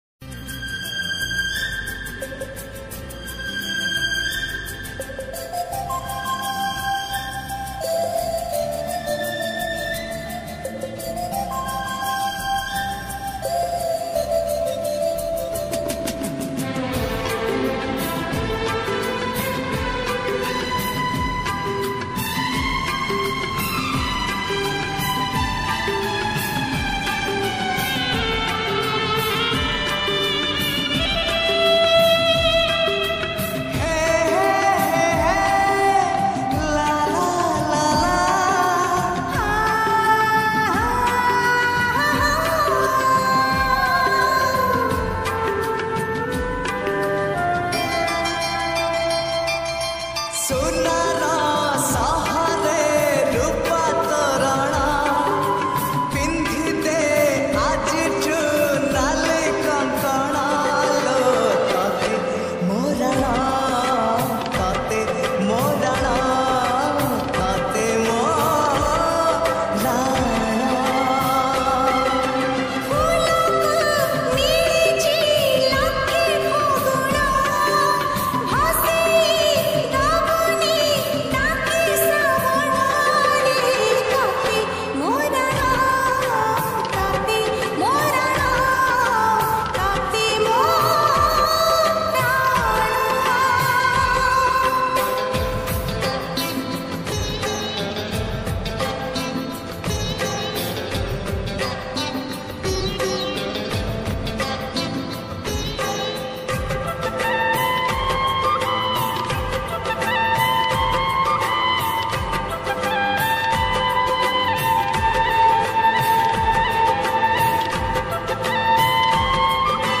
odia lofi song Songs Download